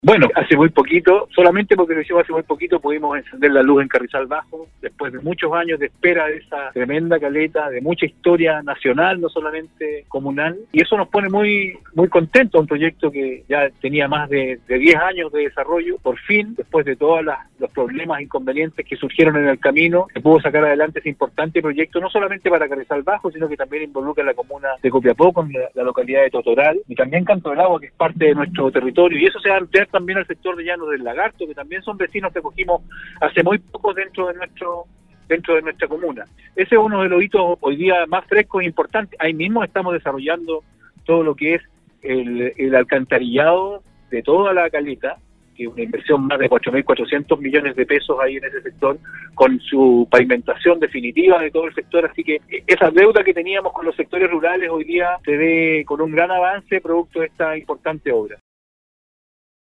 Este jueves la comuna de Huasco cumple 170 años, Nostálgica conversó con su alcalde Rodrigo Loyola quien destacó la trascendencia  de este importante hito llenó de historias y tradiciones, que han permitido el desarrollo de la comuna, que si bien es pequeña no está exenta de dificultades que con el pasar de los años han logrado ir superando.